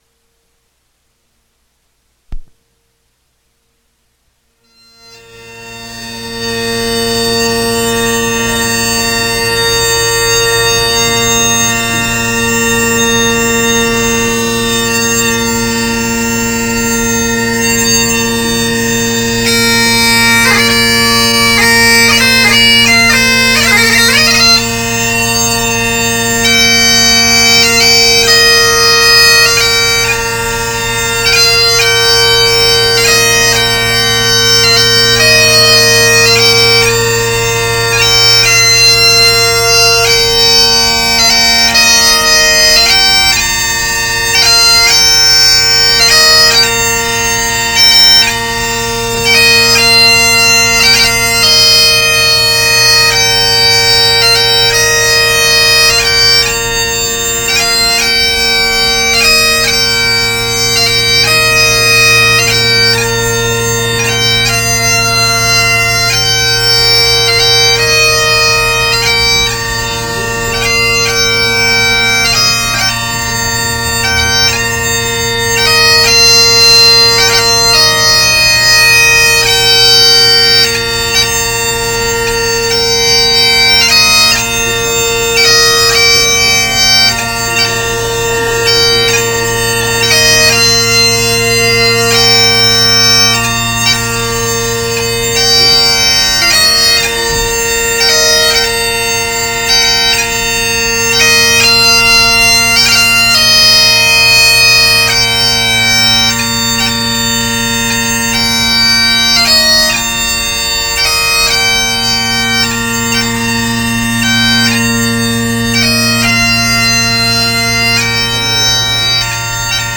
It was made in Seattle, WA, in 1955 whilst Donald was on a tour of the western US and Canada.
As can be heard in the introduction, the recital was impromptu, so much so that Donald did not have time to don his kilt.
There then follows a slow air with the characteristic sharp D of chanters of that era and then Bobby MacLeod’s 6/8 Murdo MacKenzie of Torridon.